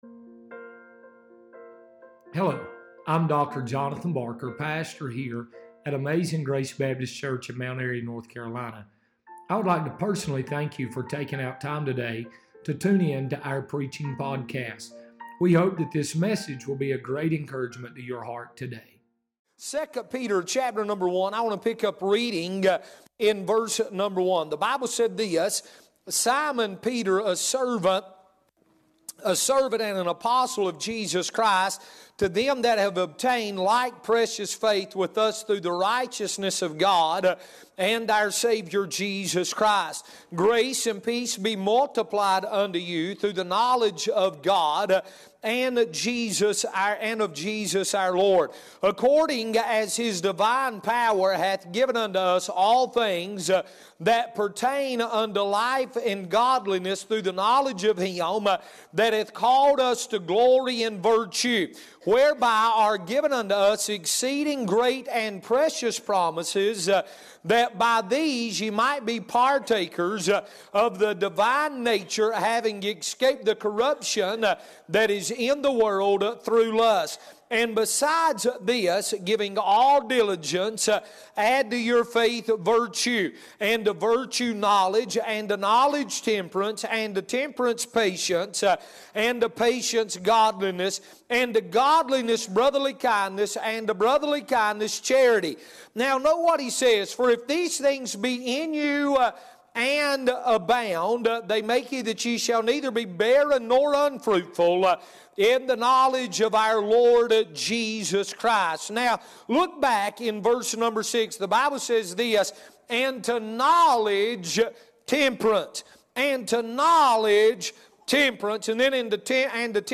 Sermons | Amazing Grace Baptist Church